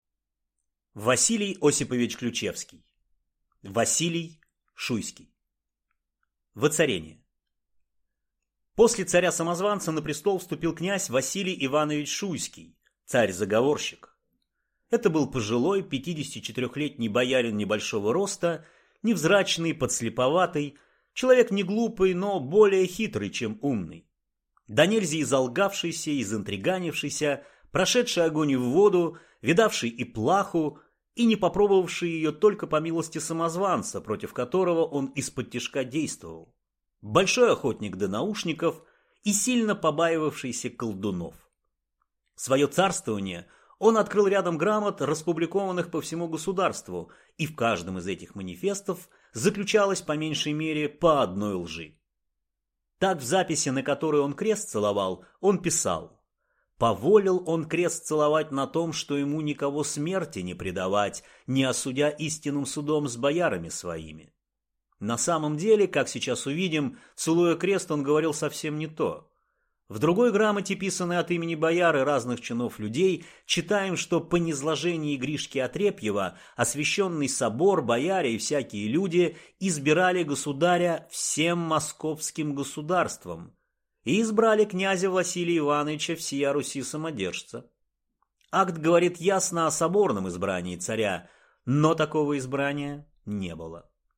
Аудиокнига Василий Шуйский | Библиотека аудиокниг
Прослушать и бесплатно скачать фрагмент аудиокниги